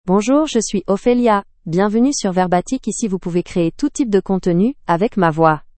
OpheliaFemale French AI voice
Ophelia is a female AI voice for French (Canada).
Voice sample
Female
Ophelia delivers clear pronunciation with authentic Canada French intonation, making your content sound professionally produced.